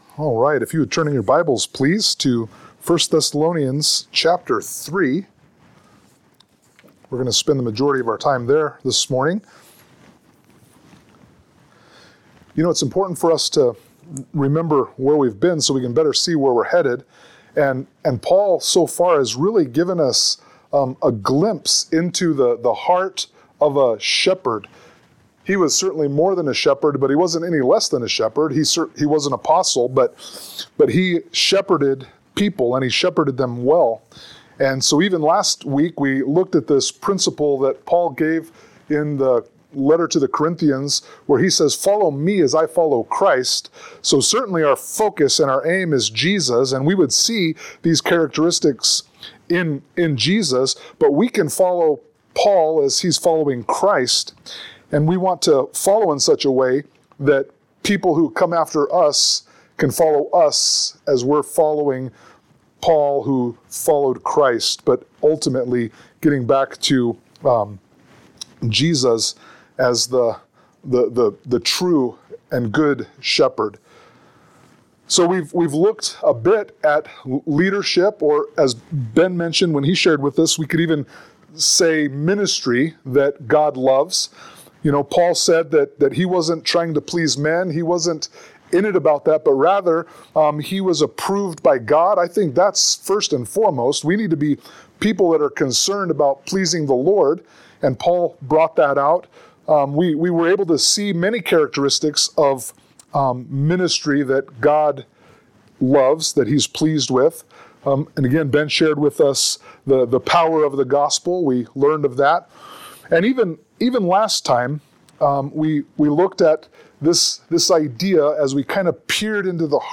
Sermon-3_16_25.mp3